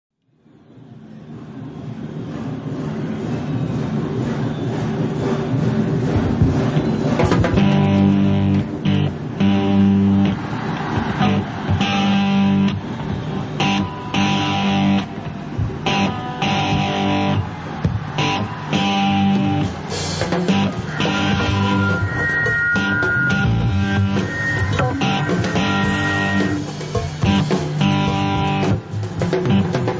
Live at Pavillon Paris France June 4th, 1976